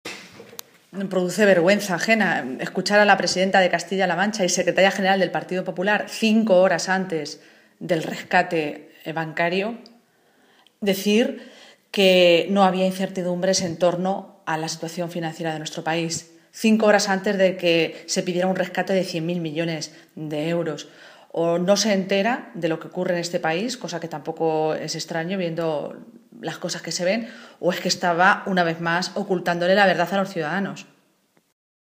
Cortes de audio de la rueda de prensa
Maestre-declaraciones_cospedal_sobre_el_rescate.mp3